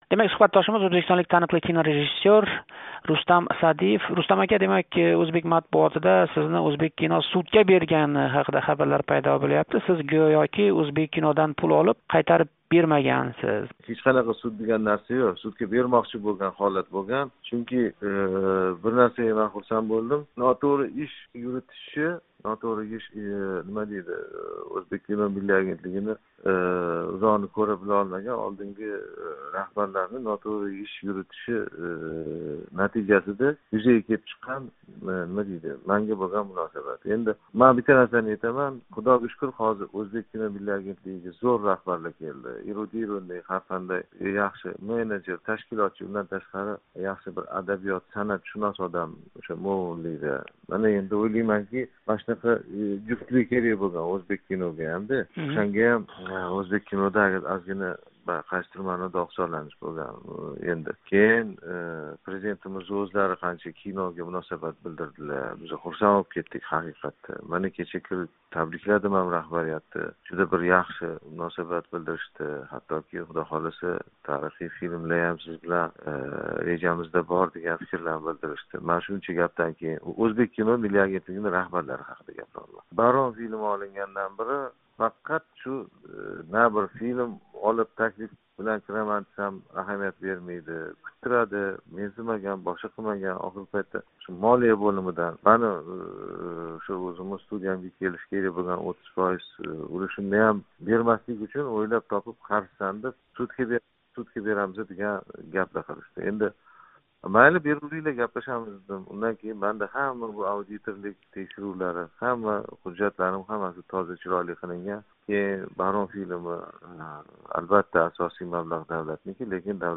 Кинорежиссёр Рустам Саъдиев билан суҳбат